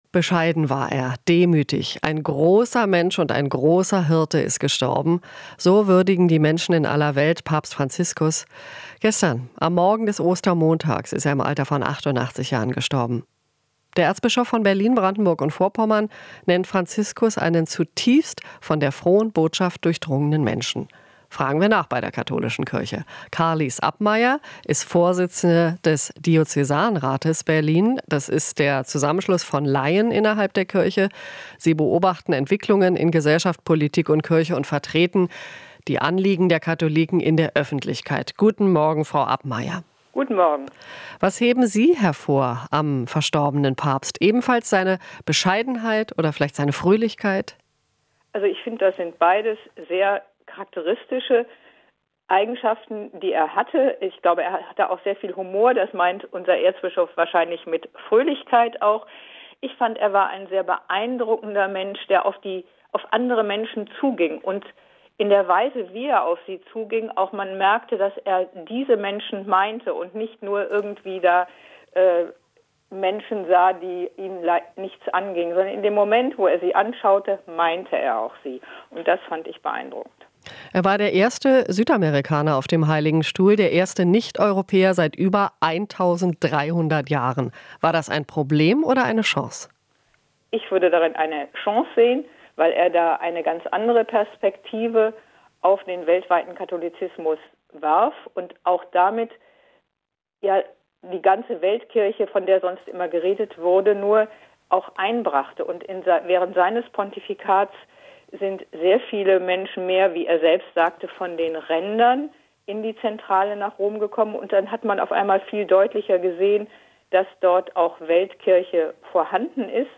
Interview - Diözesanrat Berlin: Franziskus hat viele Reformanstöße gegeben